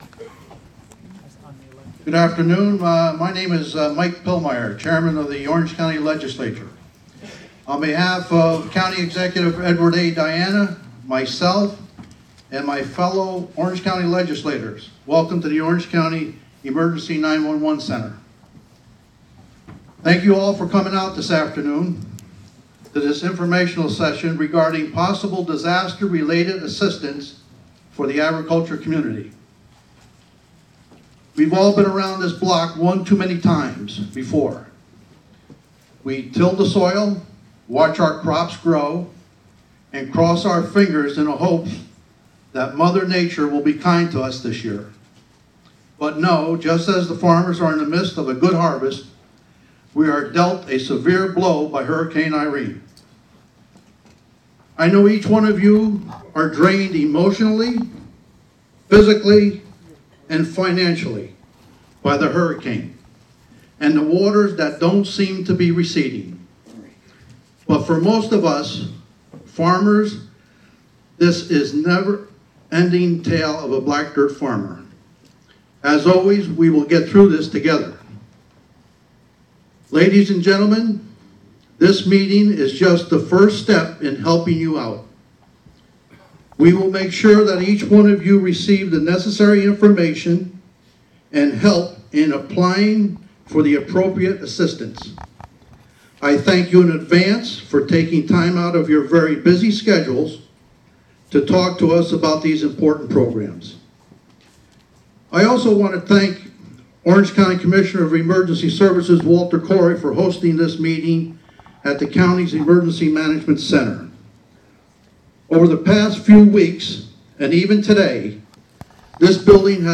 Informational session related to possible disaster-related assistance for the agricultural community, at Orange County Emergency 911 Center.
Only first half-hour recorded. With Michael Pillmeier, Orange County Legislator.